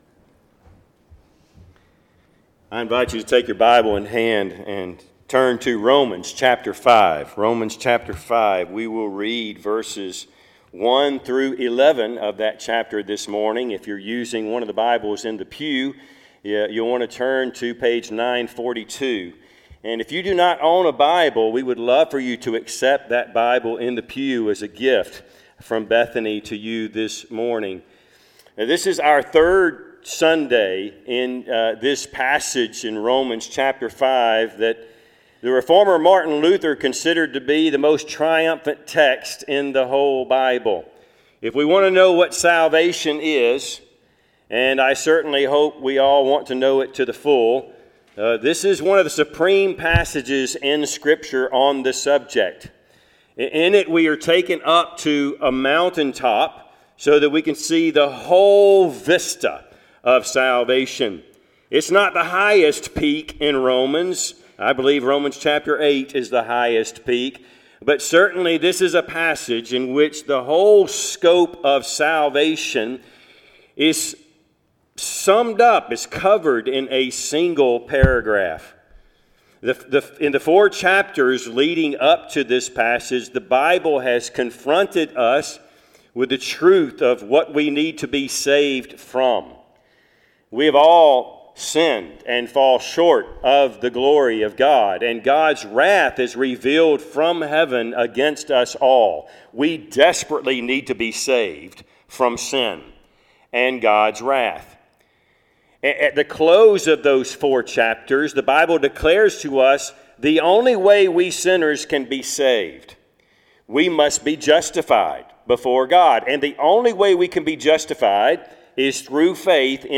Passage: Romans 5:1-11 Service Type: Sunday AM